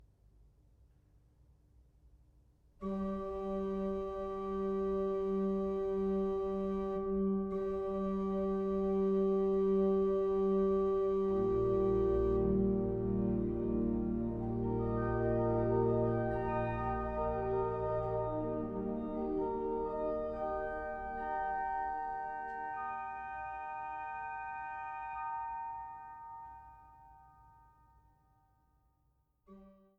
Jehmlich-Orgel in der Kirche St. Wolfgang zu Schneeberg